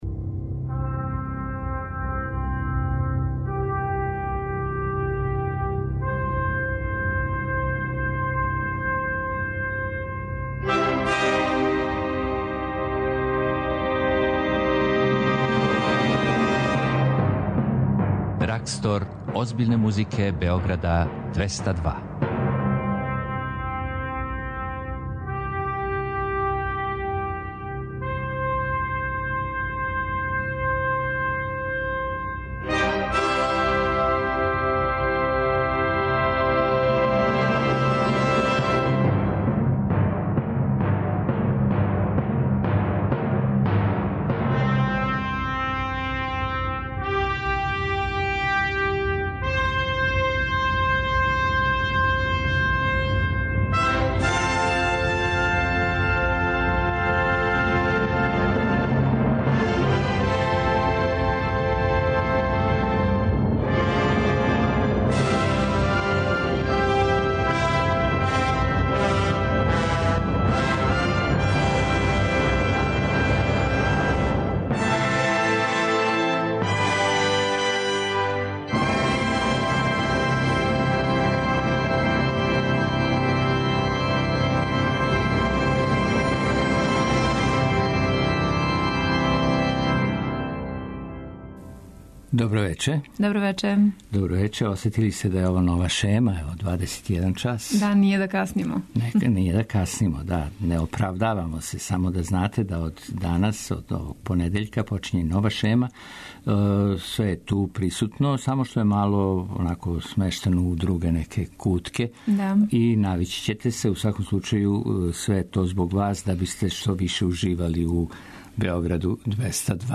То је био Готфрид Хајнрих Штолцел, а вечерас његове ноте слушамо у аранжману за трубу. До 23ч емитоваћемо дела Бетовена, Листа, Росинија и Јаначека, а на концерту из галерије Артгет слушамо дуо хармоника Олимп који ће свирати танго!